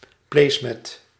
Ääntäminen
IPA : /ˈmæt/ US : IPA : /ˈmæt/